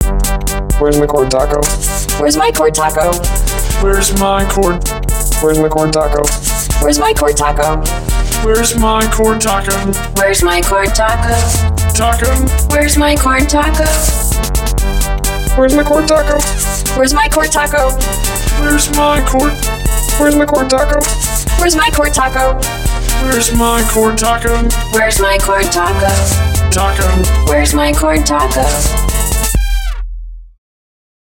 For example, the Tech Cocktail team sent their love by creating this short little Cord Taco song that underscored the importance of having your cord taco on you at all times. I nearly fell out of my chair with joyous laughter the first time I heard it.